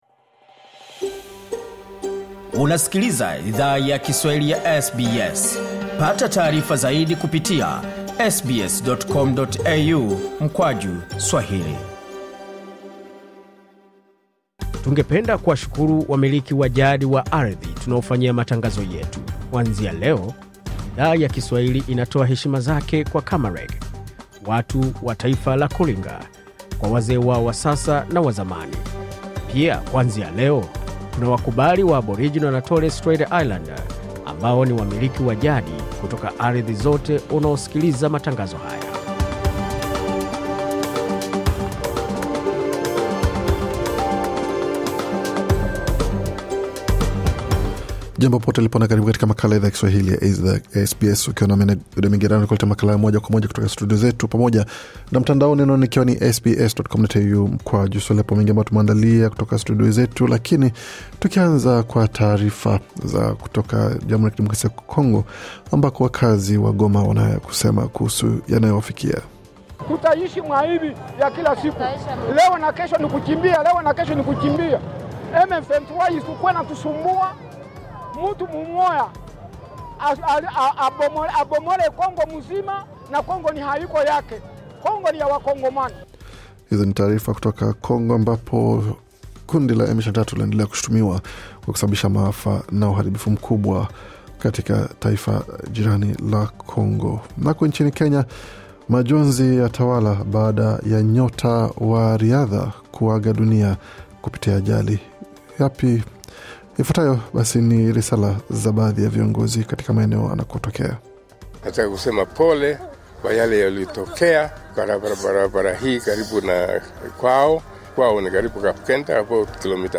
Taarifa ya Habari 13 Februari 2024